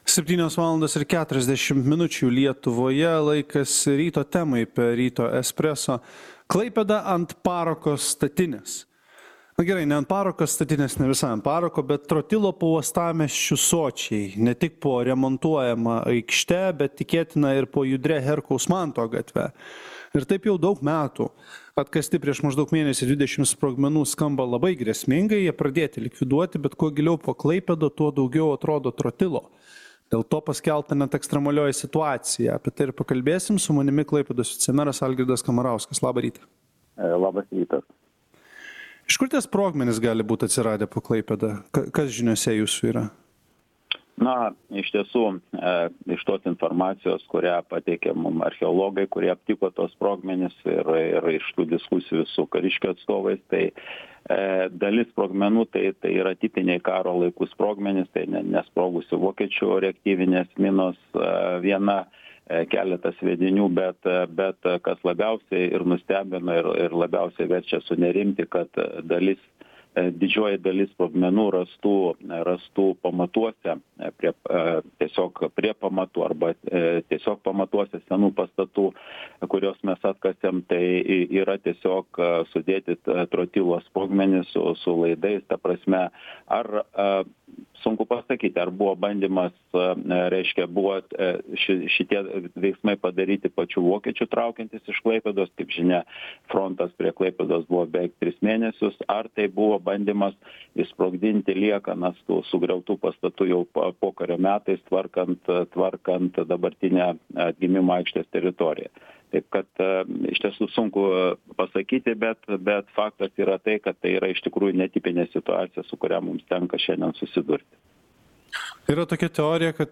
Apie neseniai aptiktų sprogmenų uostamiestyje likvidavimo planą pasakoja Klaipėdos vicemeras Algirdas Kamarauskas.